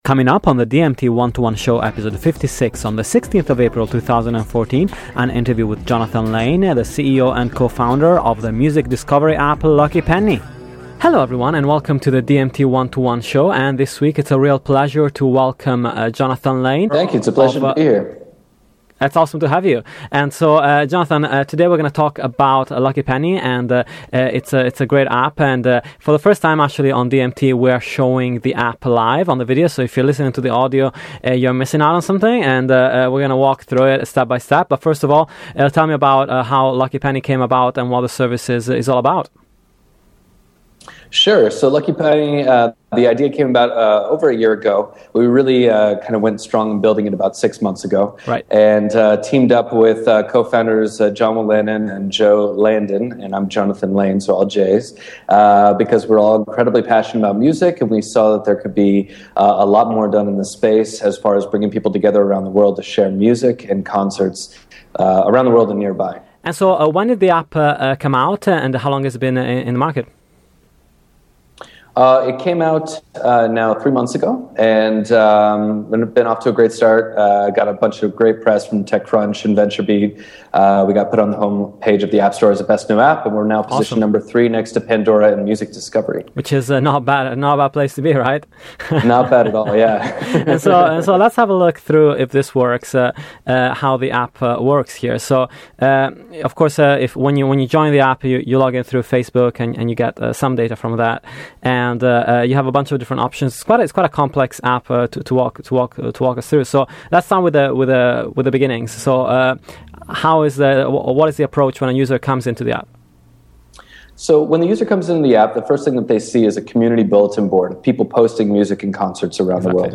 This week on the DMT 1-2-1 show an interview